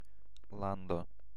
Ääntäminen
UK : IPA : [ˈkʌntri] US : IPA : [ˈkʌntri]